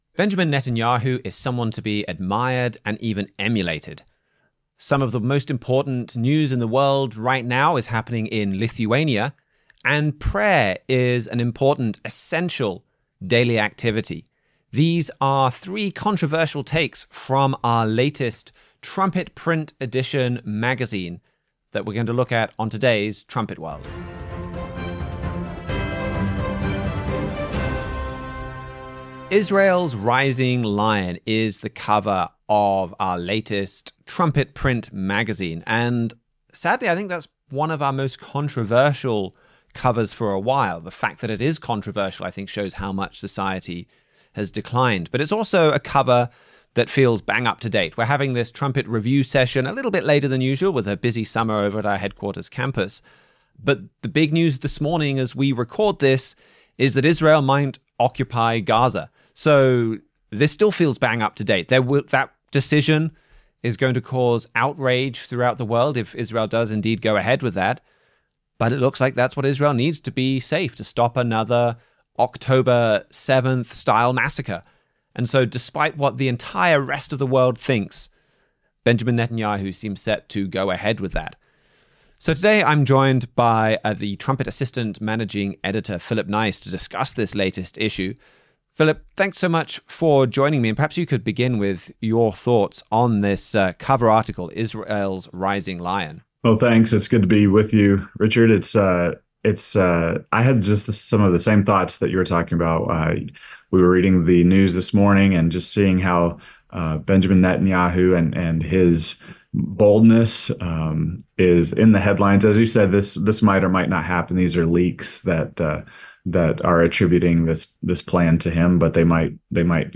Join the discussion as Trumpet staff members compare recent news with Bible prophecy.